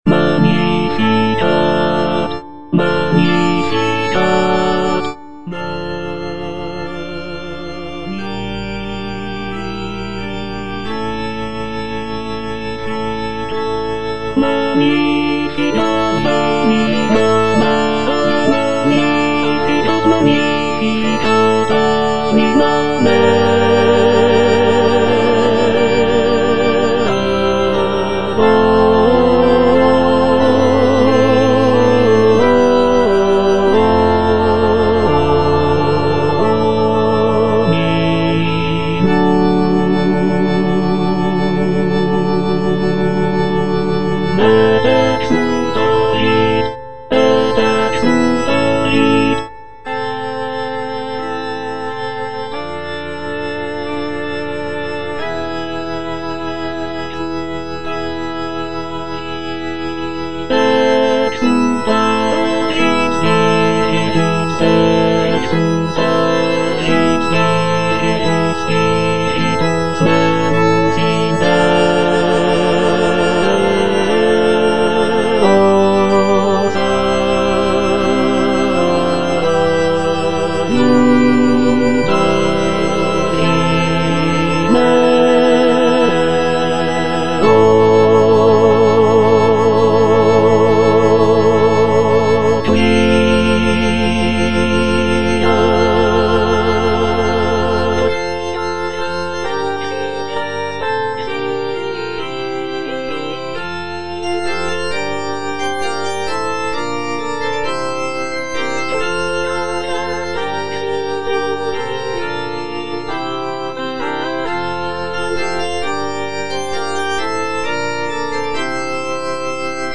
Tenor I (Emphasised voice and other voices) Ads stop